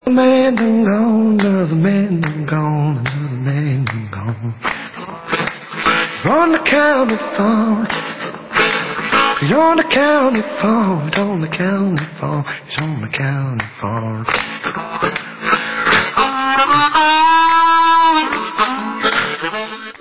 Stereo Version